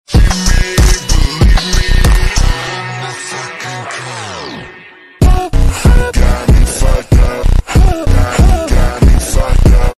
You Just Search Sound Effects And Download. tiktok funny sound hahaha Download Sound Effect Home